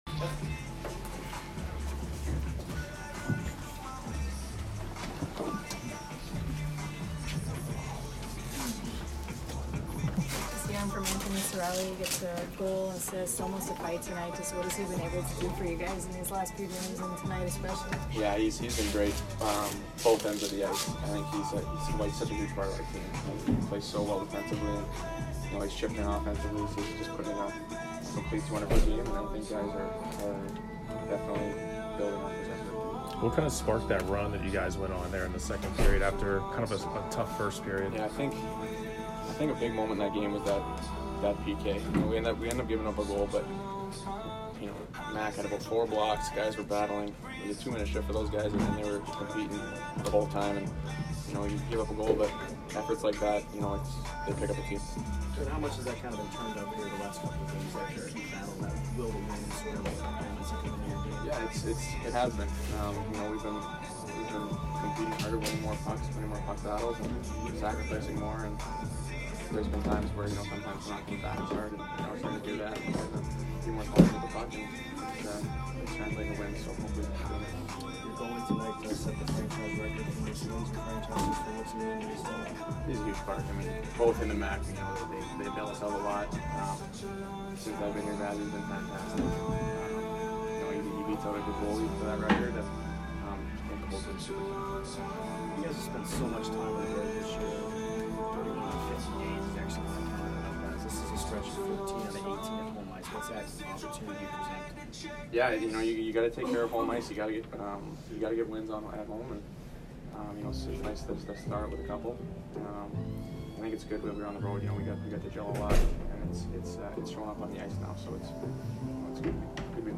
Brayden Point post-game 11/23